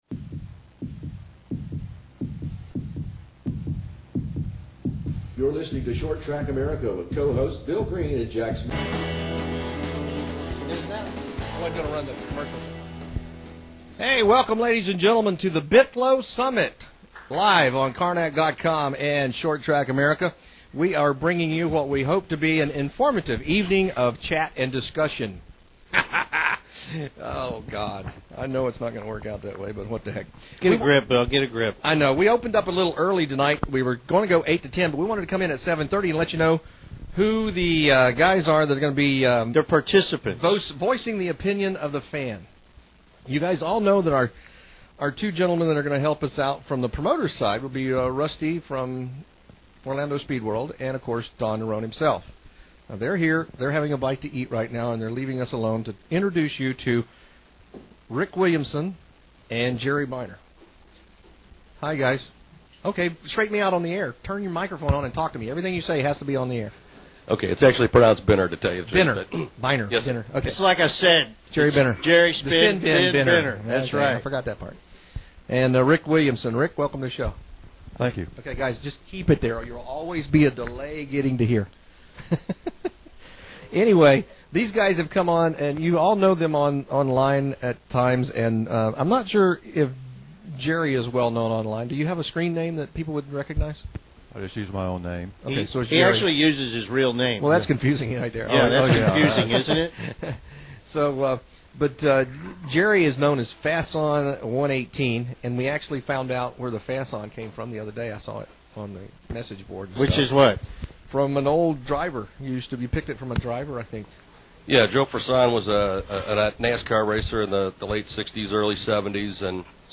Listen: 1) Pre-debate (20 min) 2) Hour One The Bithlo Summit 3) Hour Two The Bithlo Summit